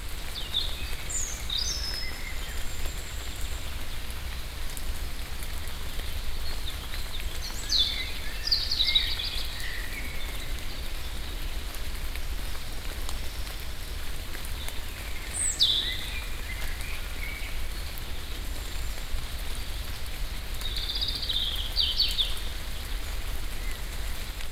rain_light.ogg